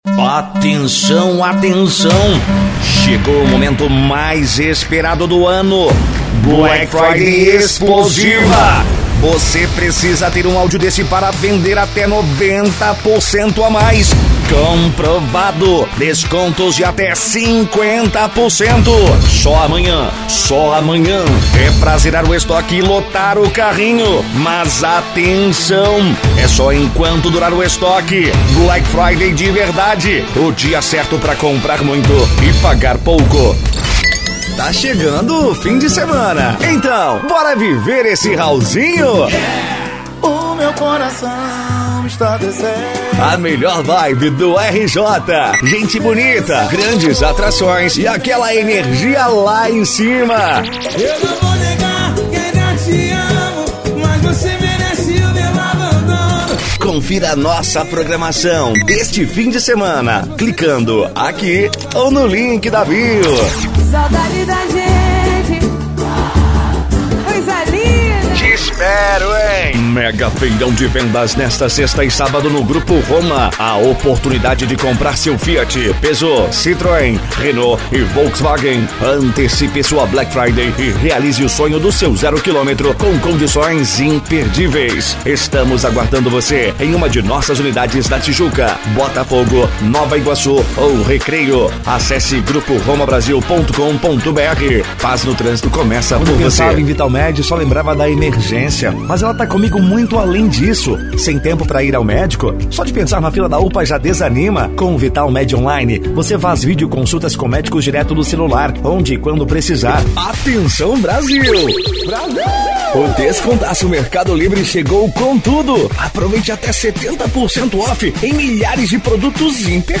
Spot Comercial
Vinhetas
Padrão
Impacto
Animada
Caricata